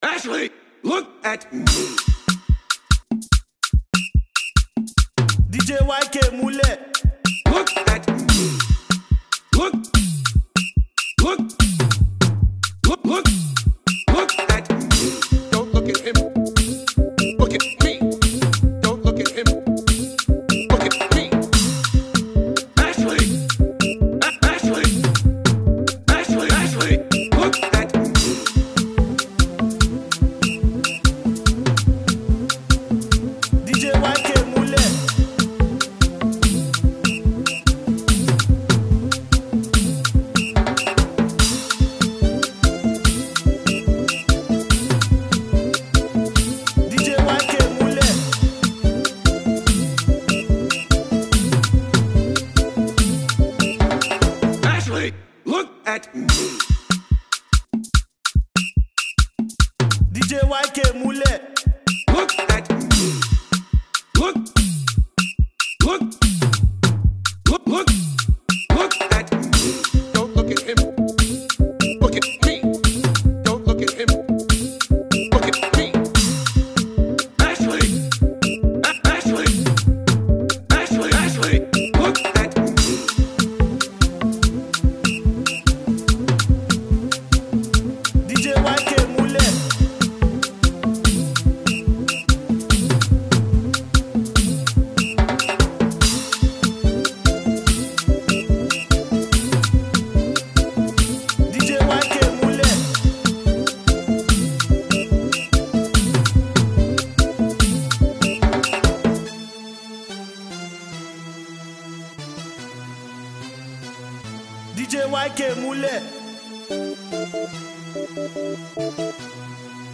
is his just another danceable tune to enjoy.